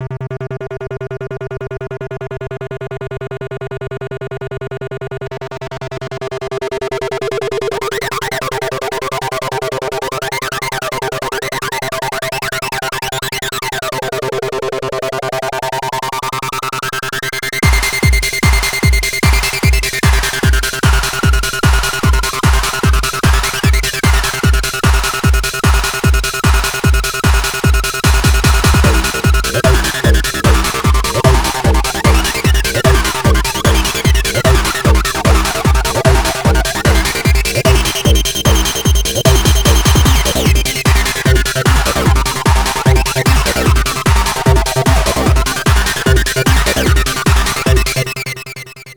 c'est de l'Acidcore, très spaciale
celle-ci c'est la plus Hard, 150 BPM